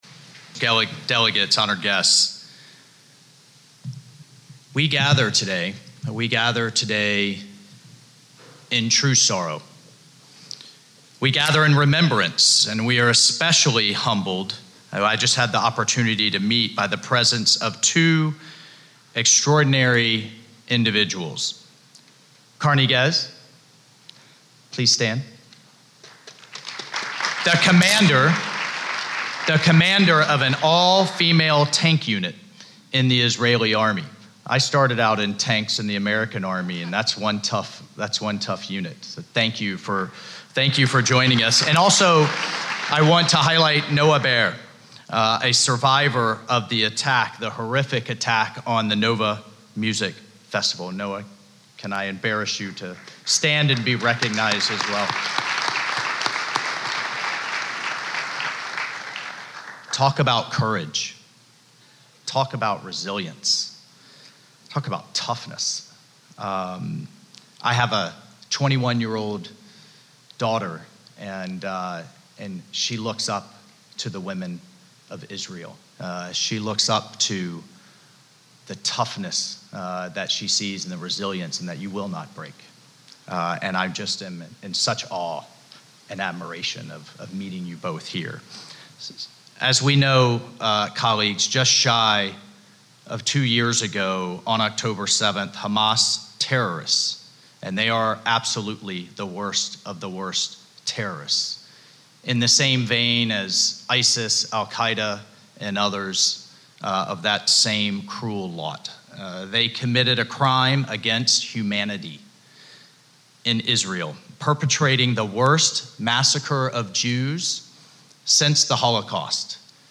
Address at a Commemoration Event Hosted by the Israeli Mission to the United Nations